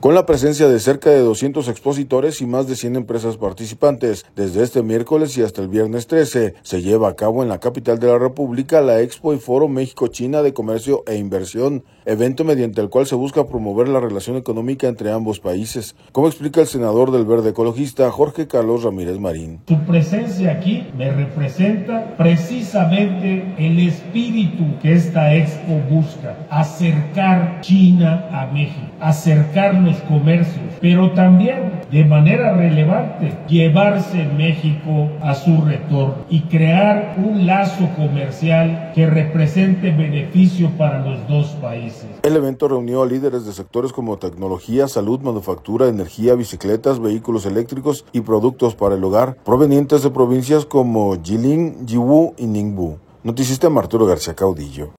audio Con la presencia de cerca de doscientos expositores y más de cien empresas participantes, desde este miércoles y hasta el viernes 13, se lleva a cabo en la capital de la República, la Expo y Foro México-China de Comercio e Inversión, evento mediante el cual se busca promover la relación económica entre ambos países, como explica el senador del Verde Ecologista, Jorge Carlos Ramírez Marín.